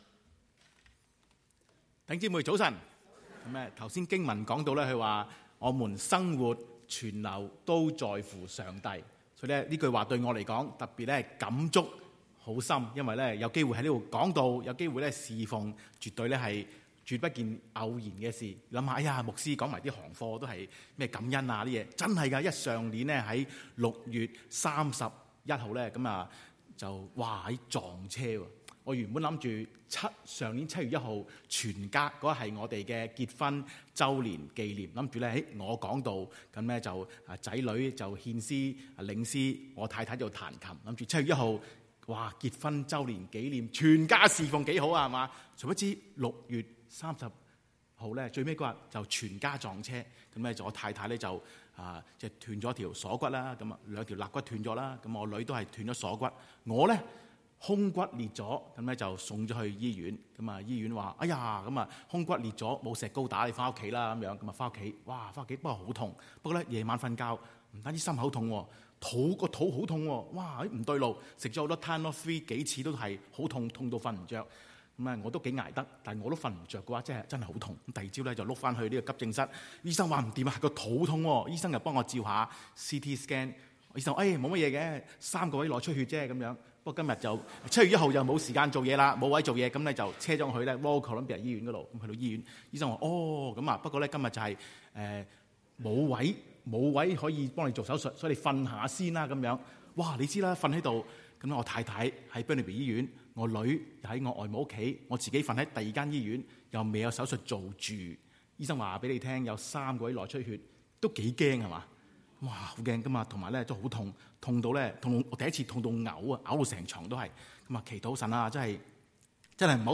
Sermons | Fraser Lands Church 菲沙崙教會